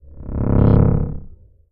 SCIFI_Energy_Pulse_06_mono.wav